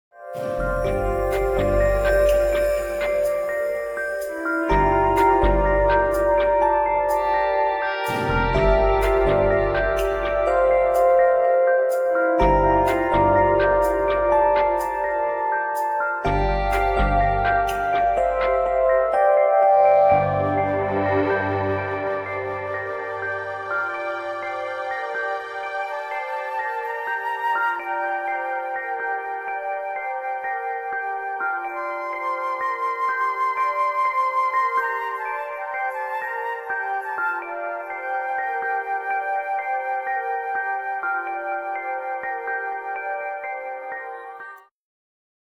Organic exotic grooves of world percussion set the tone